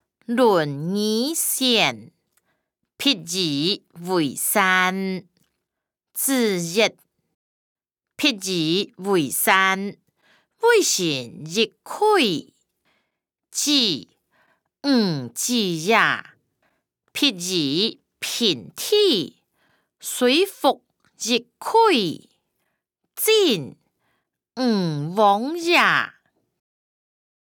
經學、論孟-論語選．譬如為山音檔(大埔腔)